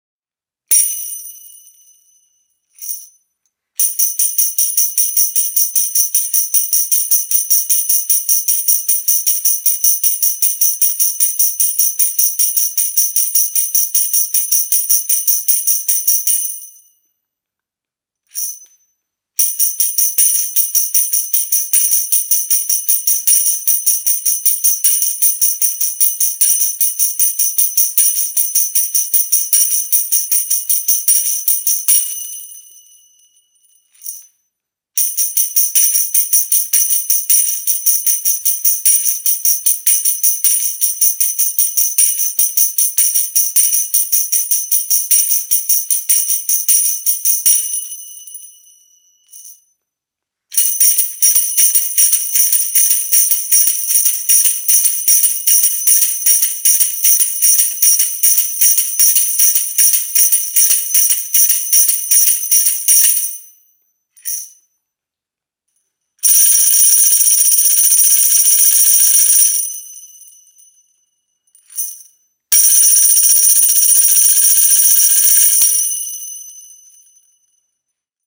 MEINL Percussion Traditional Wood Series Tambourine - 10" (TA1B-AB)
Solid brass jingles deliver a distinctively warm, and cutting sound.